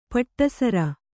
♪ paṭṭa sara